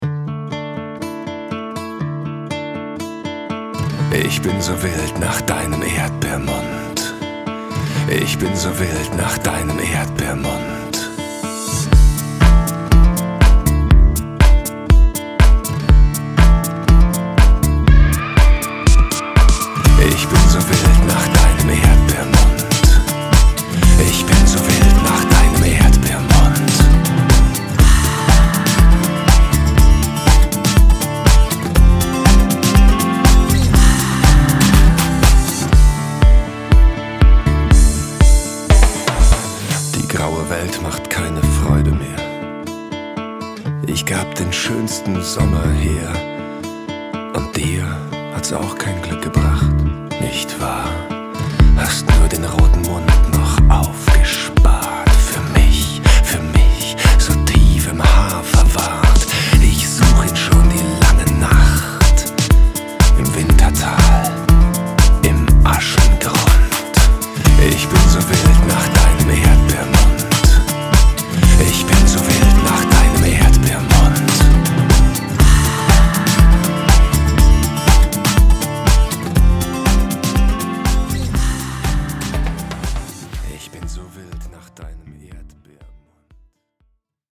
Vocals
Gitarre